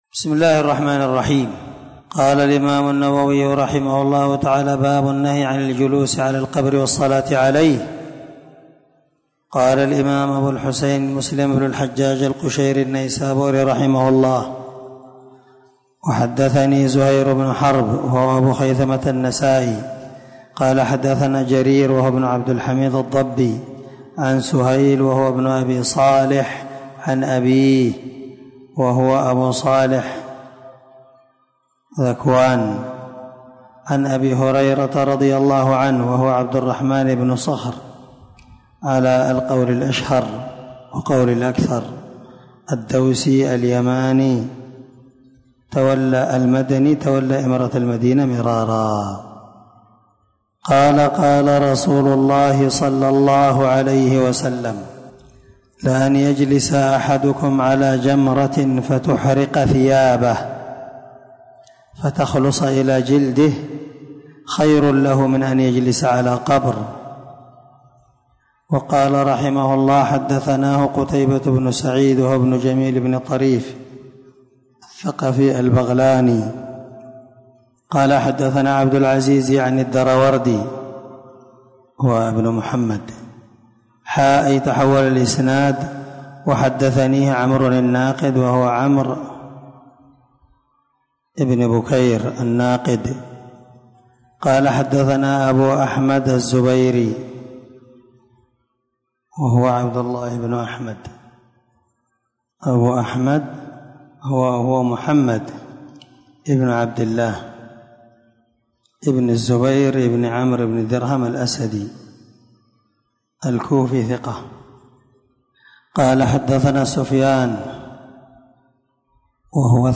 587الدرس 32من شرح كتاب الجنائز حديث رقم(971_972) من صحيح مسلم
دار الحديث- المَحاوِلة- الصبيحة.